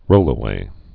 (rōlə-wā)